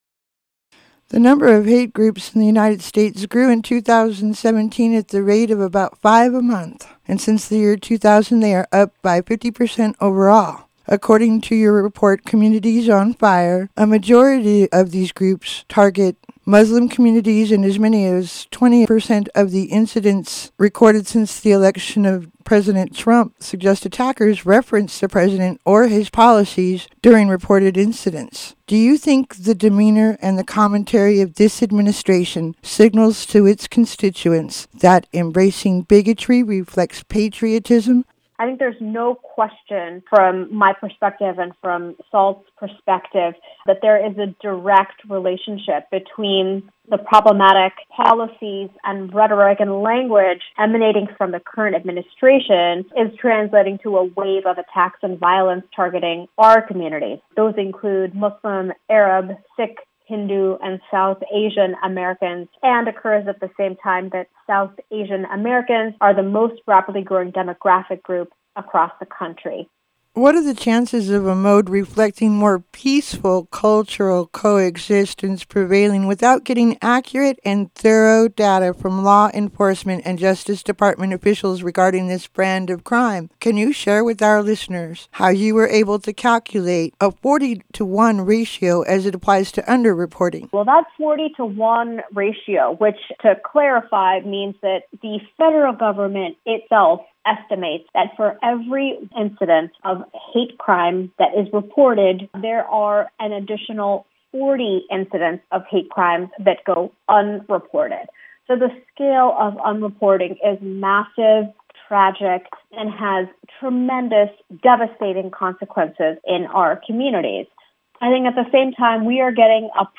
Hate Groups on the Rise KGNU News